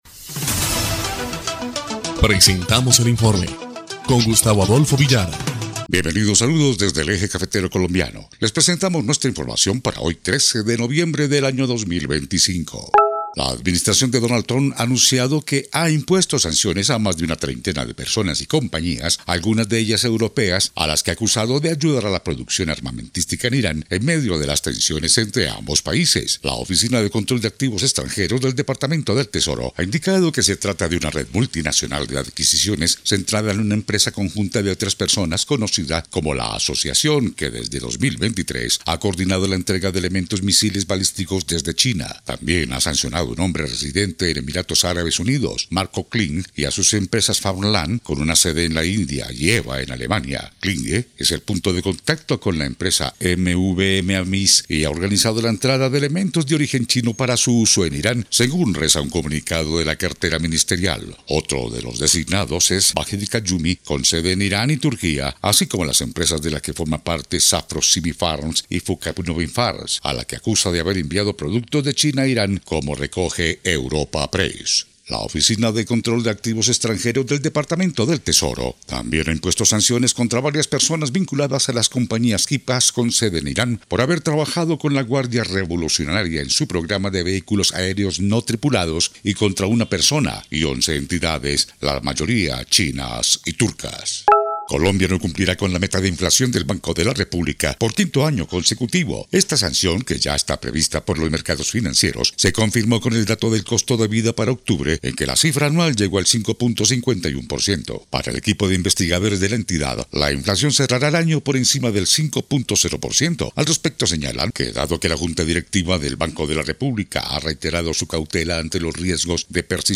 EL INFORME 2° Clip de Noticias del 13 de noviembre de 2025